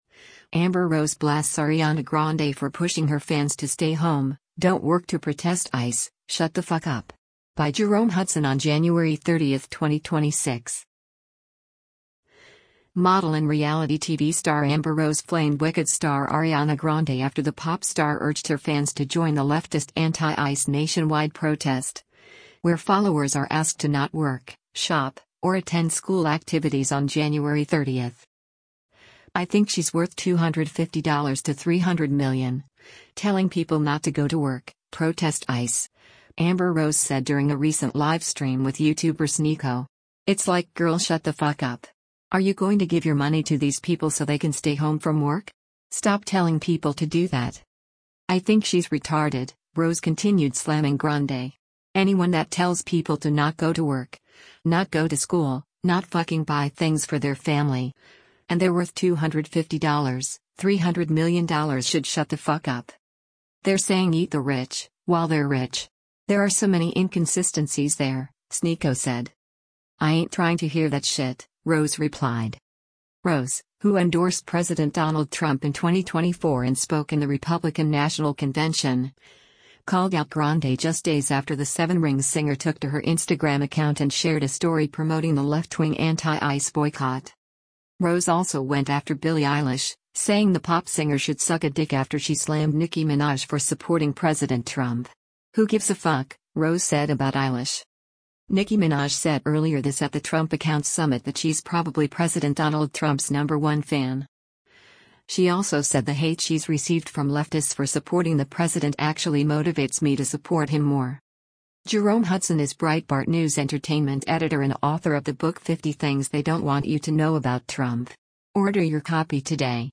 “I think she’s worth $250 to 300 million, telling people not to go to work, protest ICE,” Amber Rose said during a recent livestream with YouTuber SNEAKO.